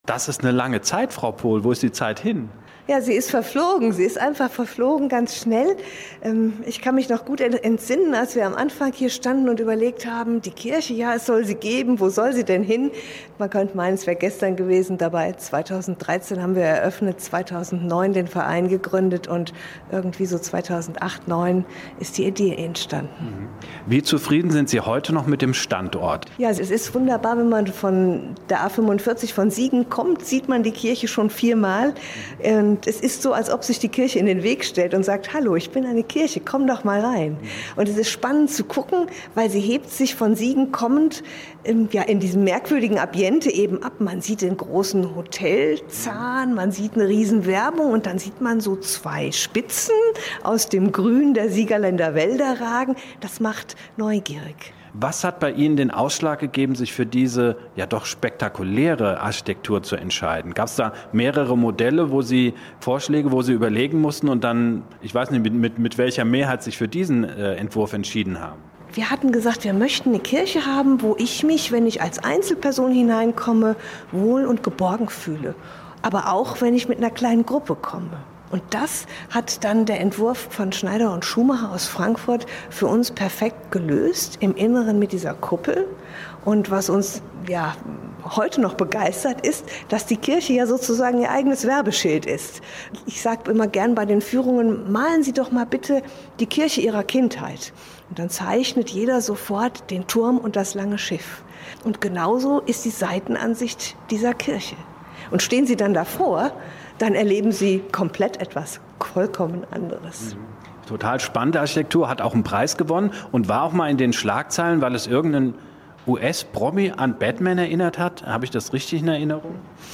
stz---0603-autobahnkirche-interview-v3.mp3